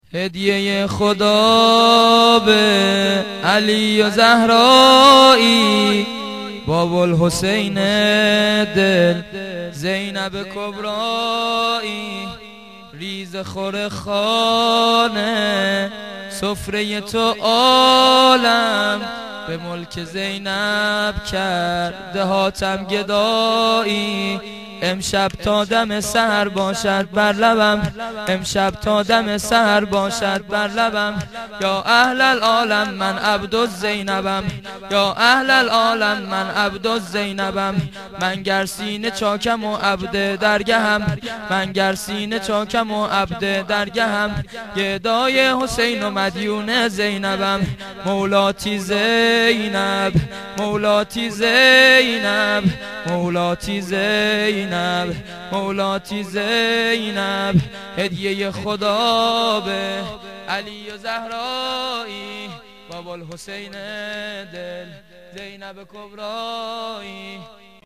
سرود میلاد*من عبد زینبم